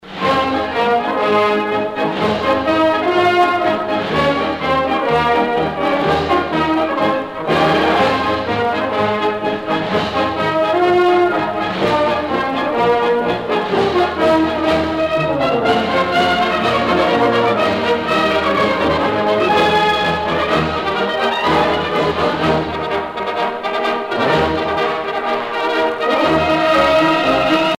gestuel : à marcher
circonstance : militaire